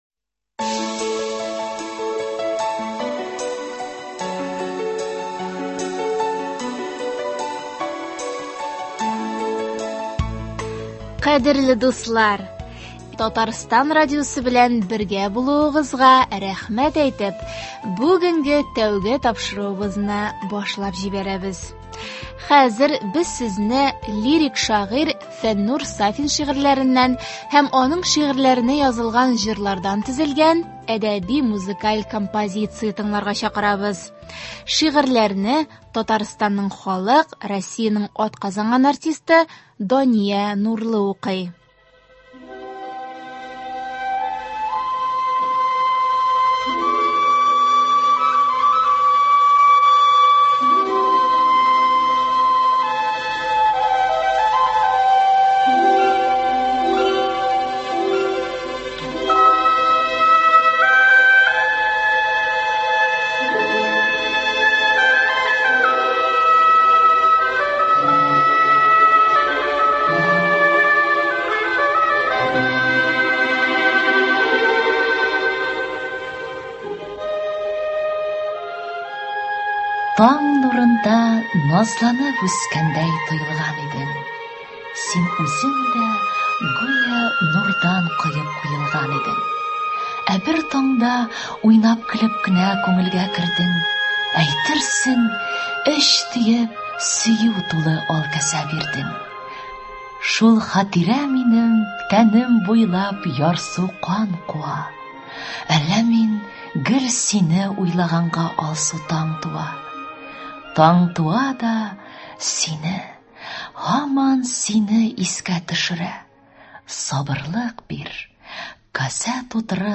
Хәзер без сезне лирик шагыйрь Фәннүр Сафин шигырьләреннән һәм аның шигырьләренә язылган җырлардан төзелгән әдәби-музыкаль композиция тыңларга чакырабыз.